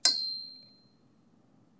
ding_01
alarm ding dong metal metallic notification notify ping sound effect free sound royalty free Sound Effects